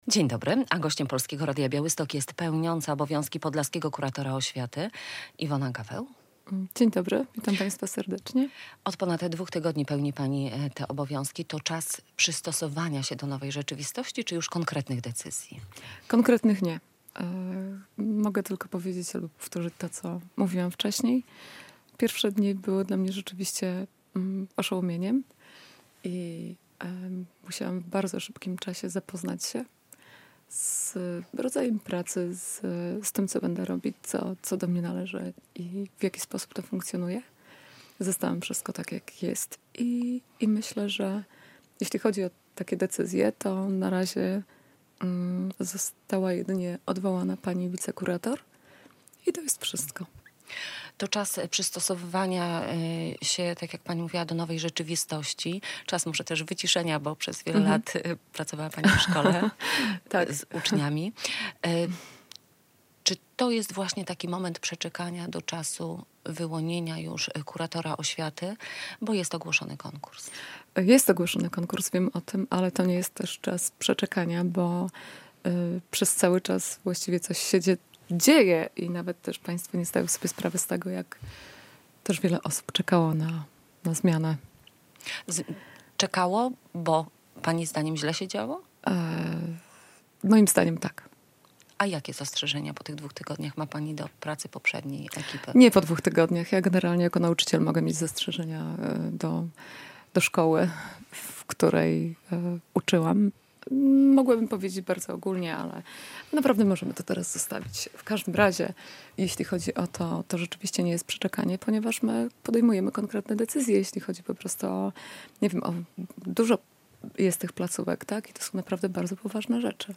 Radio Białystok | Gość | Iwona Gaweł [wideo] - pełniąca obowiązki podlaskiego kuratora oświaty